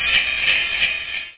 jingles.mp3